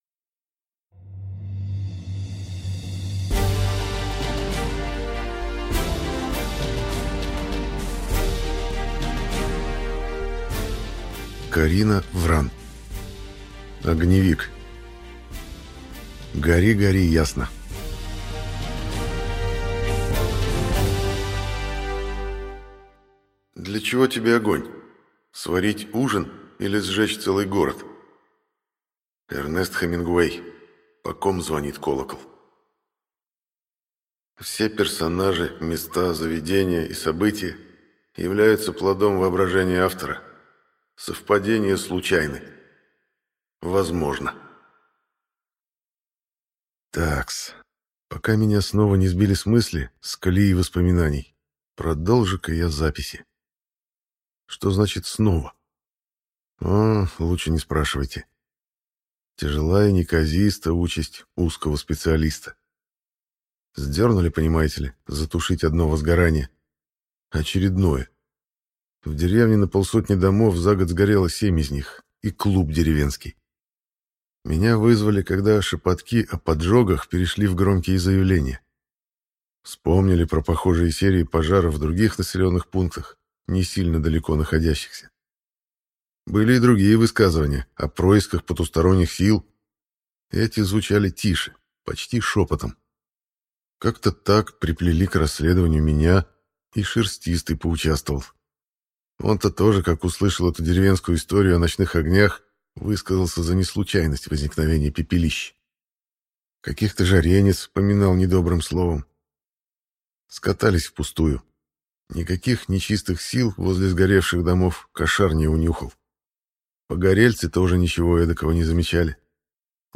Аудиокнига Гори, гори ясно | Библиотека аудиокниг